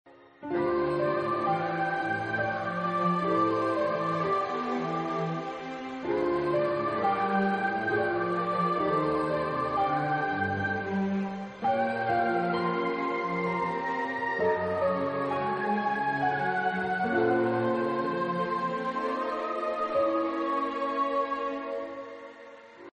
(And yes, that’s my voice :3)